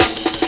amen pt-2 snare.wav